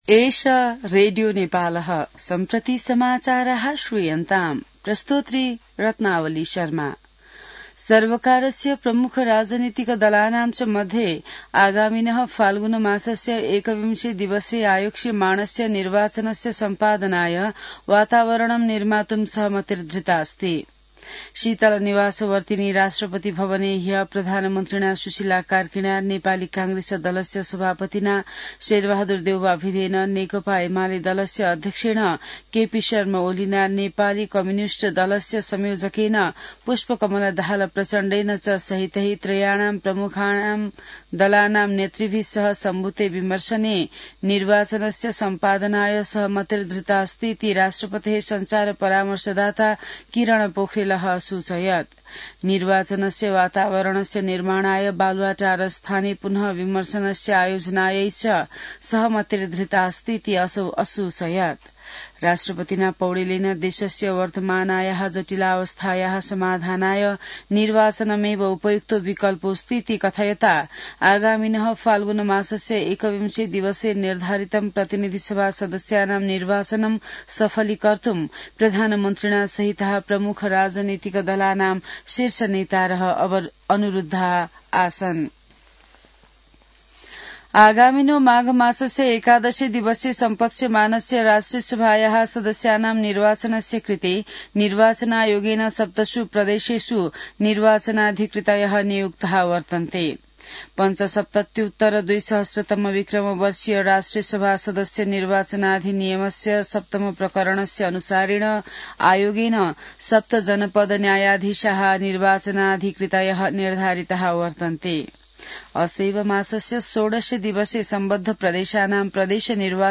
संस्कृत समाचार : ९ पुष , २०८२